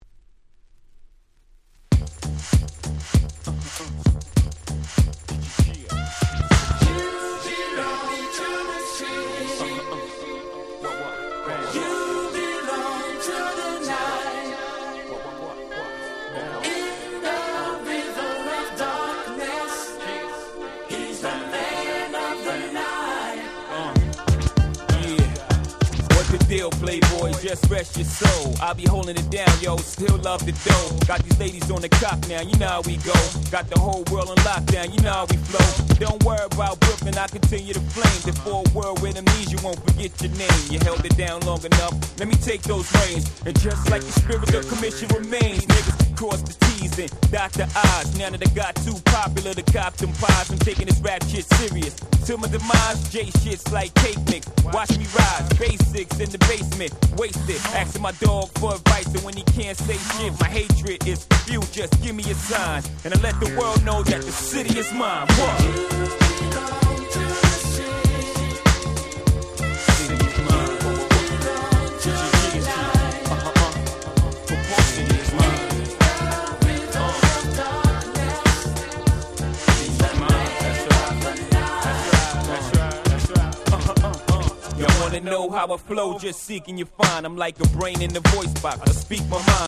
98' Smash Hit Hip Hop !!